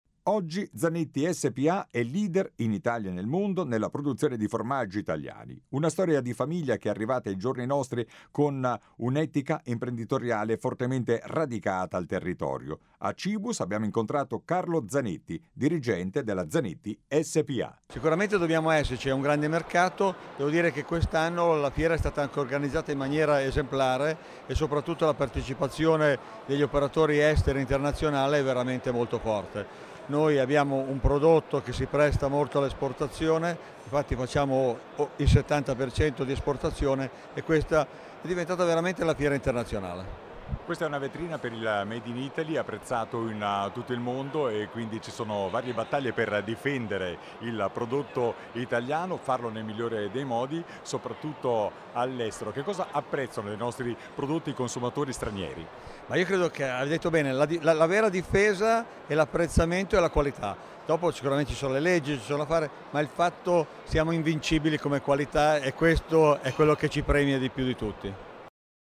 Qui di seguito le dichiarazioni raccolte dal nostro inviato sul posto